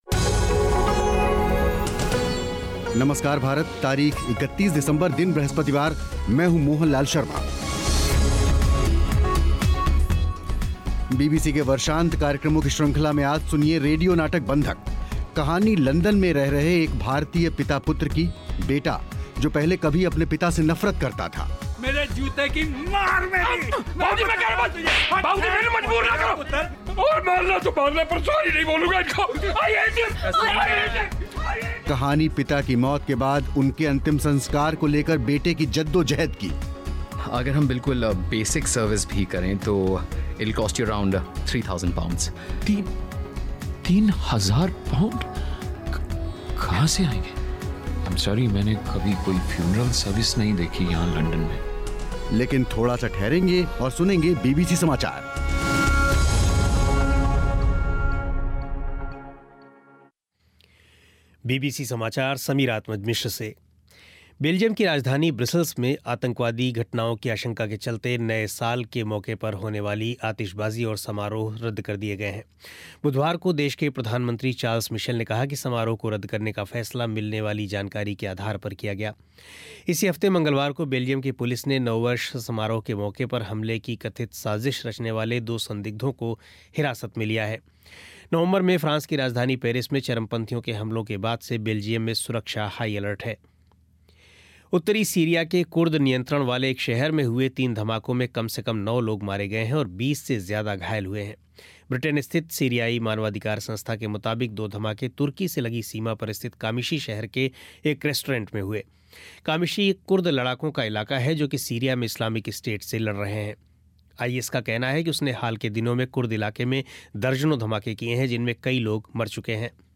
रेडियो नाटक